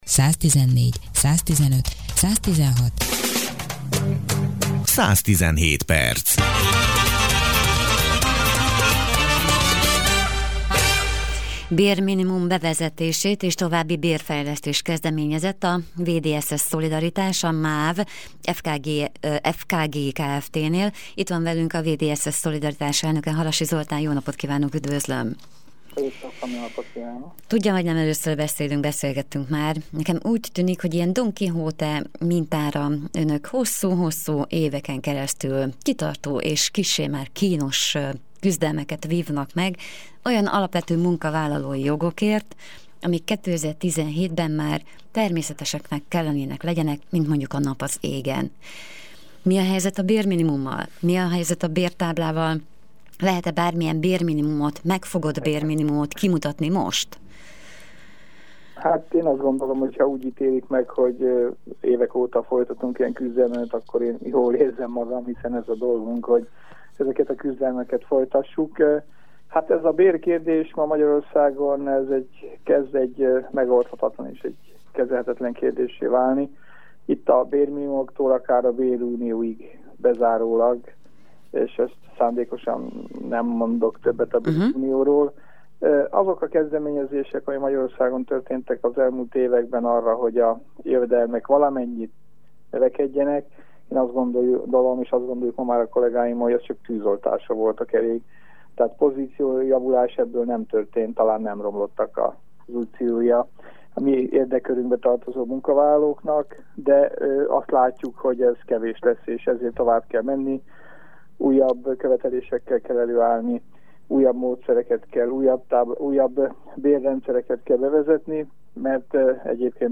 az interjút